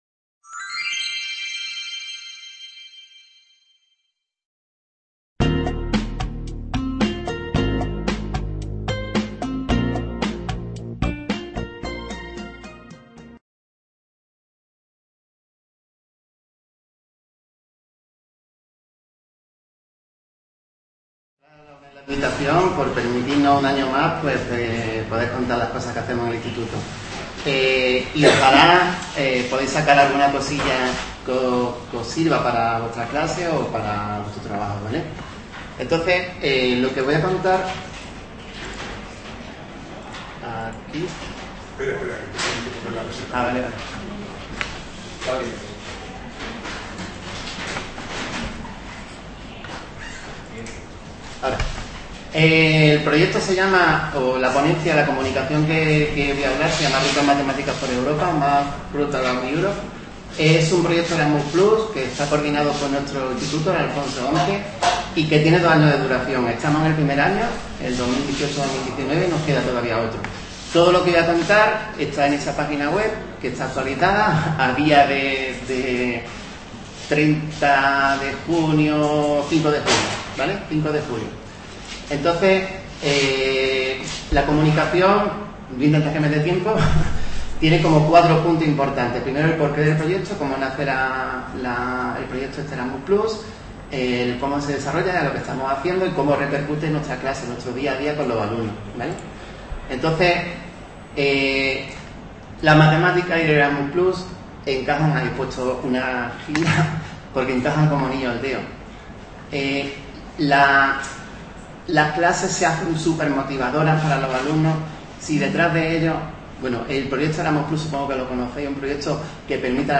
Estas Jornadas pretenden, como ya lo hiciese en los tres últimos años 2016, 2017 y 2018, ser un punto de encuentro para compartir experiencias educativas reales en Estadística y/o Matemáticas que se enmarquen en el ámbito cotidiano de la docencia en los diferentes niveles de la educación Matemática aunque está abierto a docentes de otras materias.